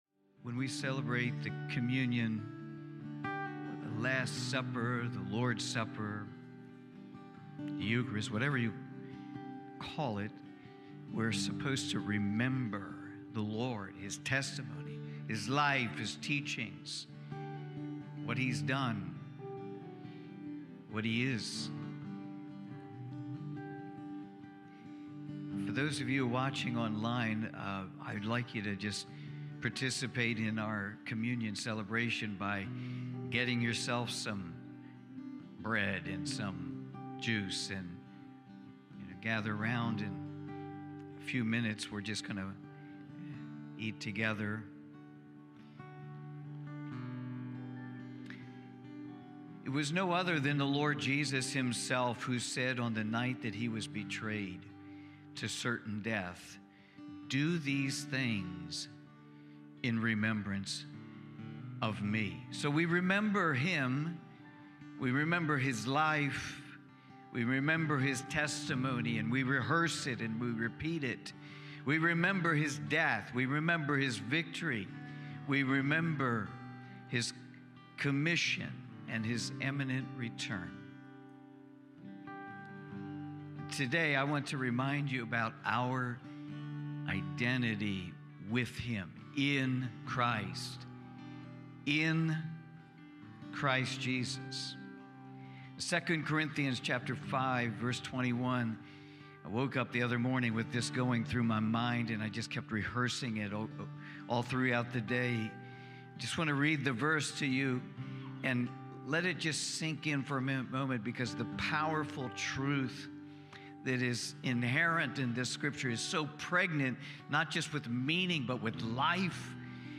Cornerstone Fellowship Sunday morning service, livestreamed from Wormleysburg, PA. Communion portion (see next message for the sermon on this date).